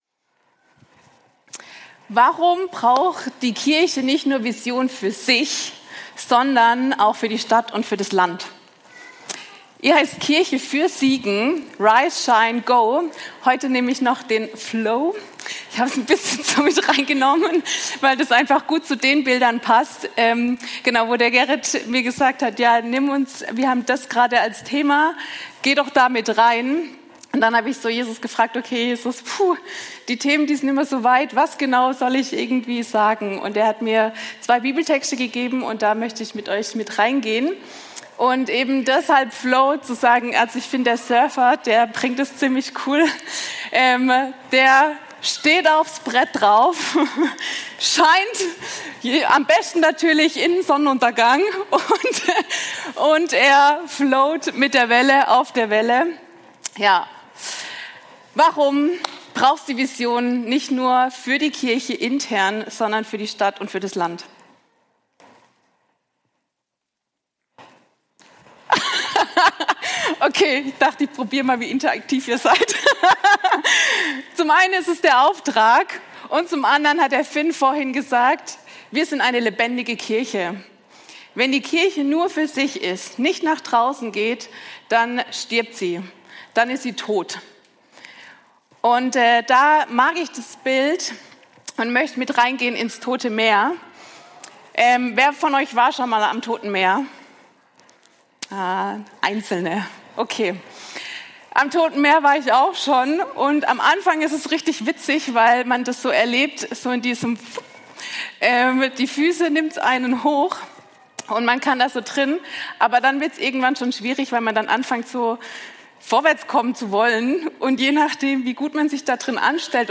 Predigt vom 15.02.2026 in der Kirche für Siegen